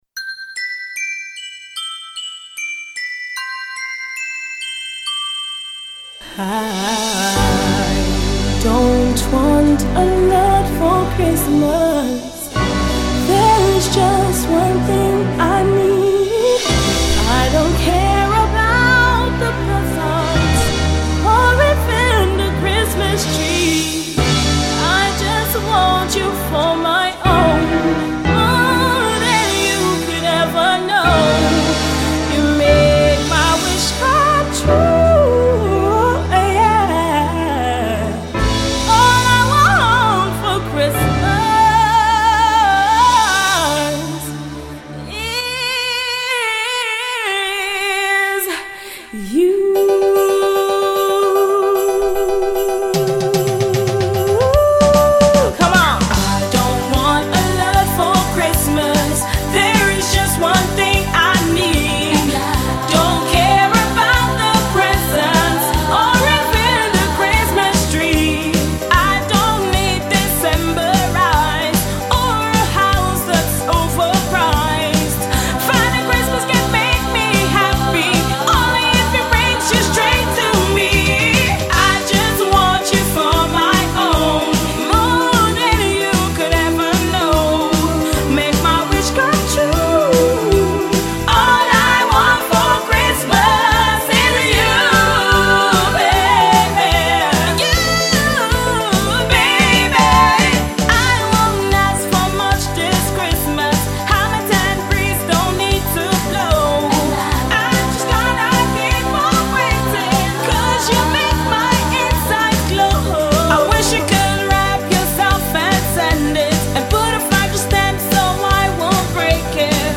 This isn’t any old cover, it has been switched up!